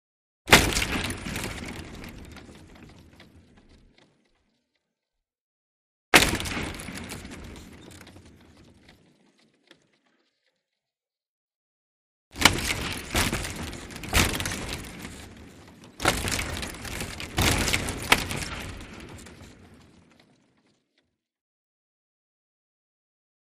Hockey: Body / Board Impact ( Multiple ); Hockey Player Impacting Glass Wall; Three Times, Loud Low Frequency Impact / Slap Echo / Long Sustaining Shaking Of Glass, Close Perspective.